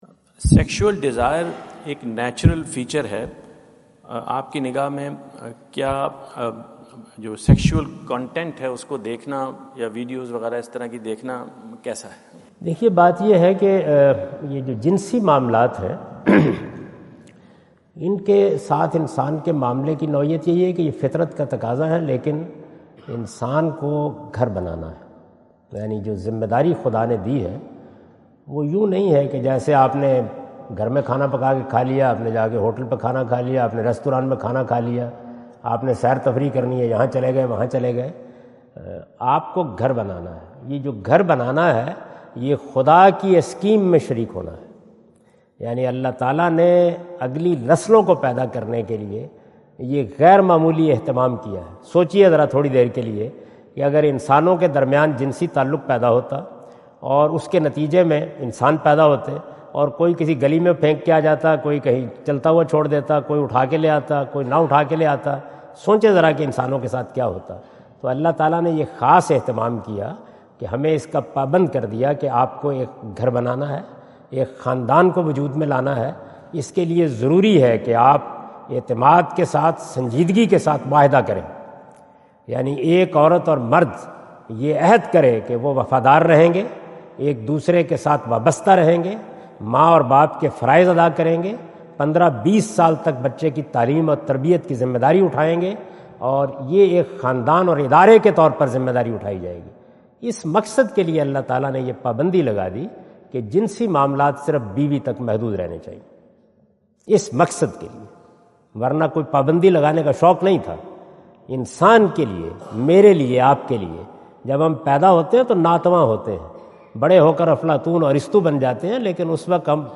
Javed Ahmad Ghamidi answer the question about "Sexual desire is natural, is it allowed to watch sexual content?" During his US visit at Wentz Concert Hall, Chicago on September 23,2017.